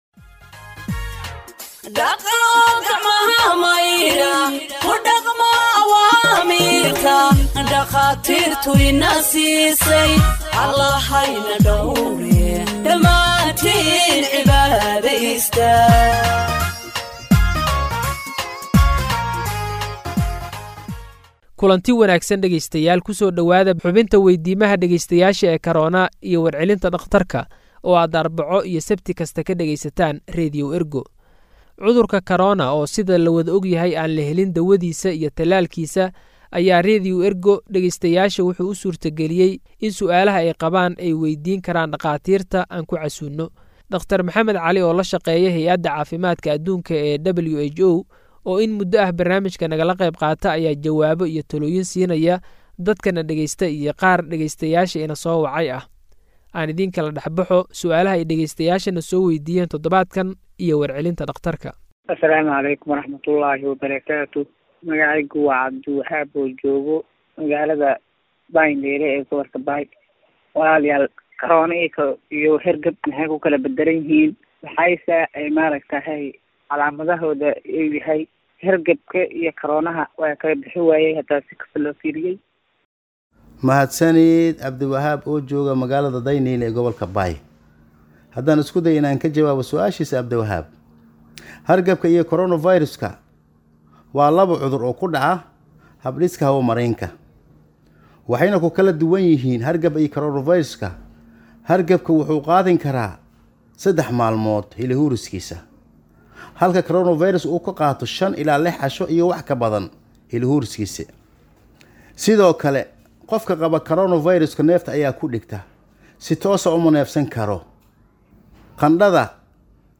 Health expert answers listeners’ questions on COVID 19 (34)